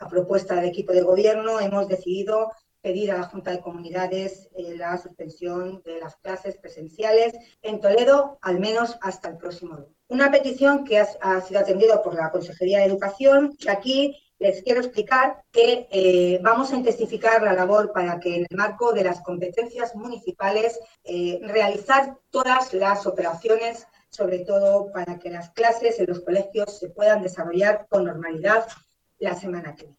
La alcaldesa de Toledo, Milagros Tolón, ha comparecido este martes para dar a conocer las últimas informaciones de las que dispone sobre el dispositivo que trabaja para paliar los efectos de la borrasca Filomena a su paso por la ciudad así como el estado de los diferentes servicios e infraestructuras municipales.